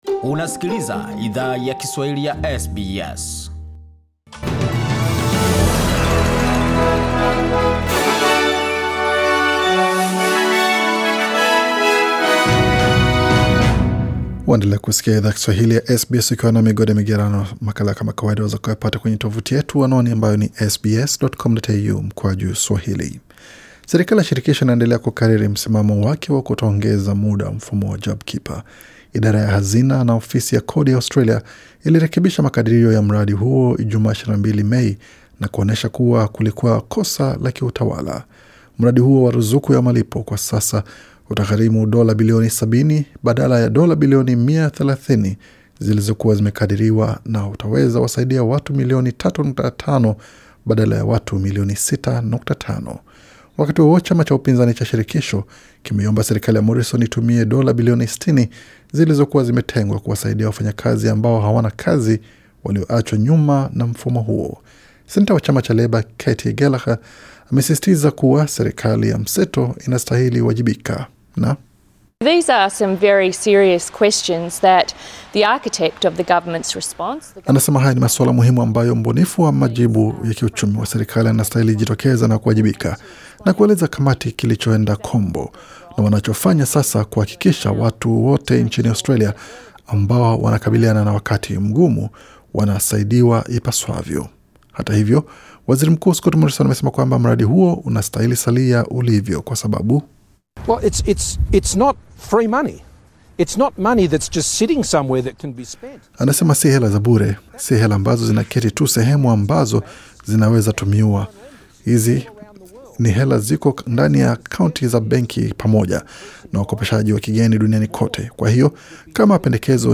Taarifa za habari 24 Mei 2020